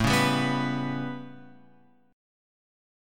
A9 chord {5 4 5 4 x 7} chord